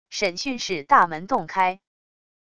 审讯室大门洞开wav音频